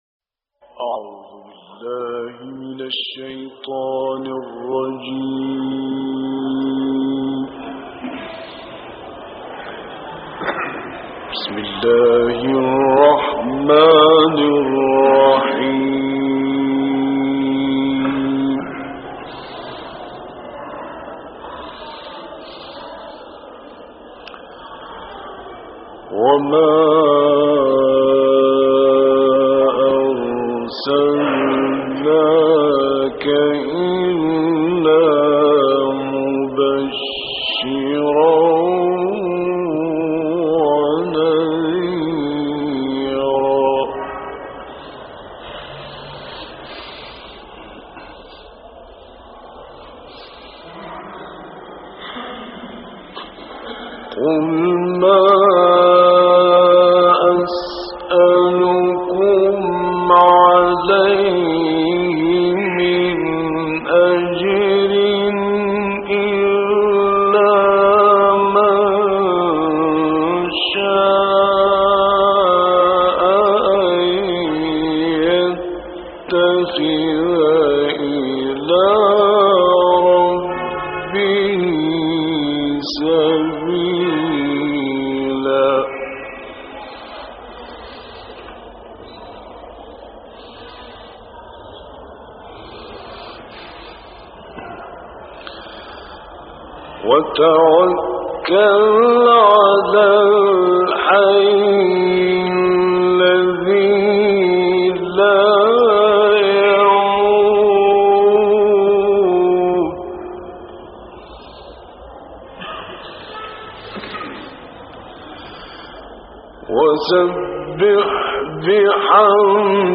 دانلود قرائت سوره فرقان آیات 56 تا آخر - استاد راغب مصطفی غلوش